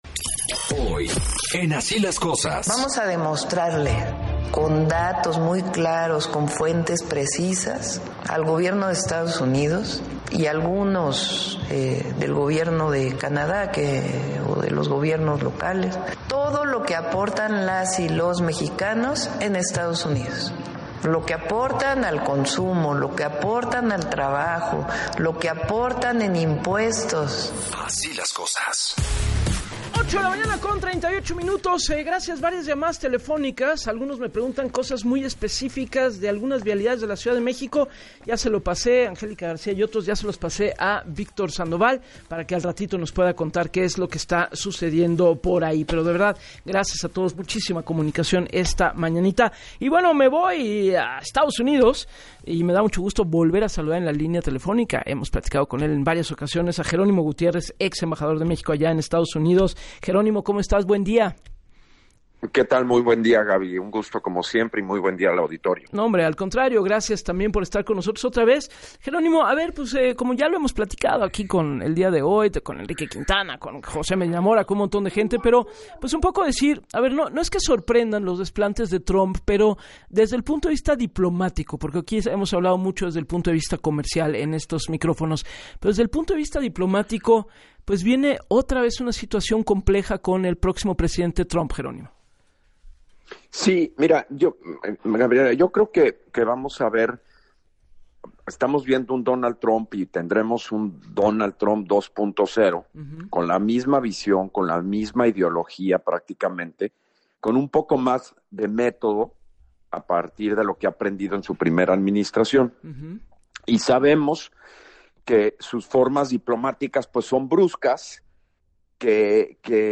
En entrevista para “Así las Cosas” con Gabriela Warkentin, aseguró “estamos viendo al Donald Trump 2.0 con la misma ideología, pero más método, sus formas diplomáticas son bruscas y poco ortodoxas”, pero aseguró “son el banderazo de salida de una negociación completamente previsible, proceso de revisión del T-MEC, contaminado con tema son comerciales”.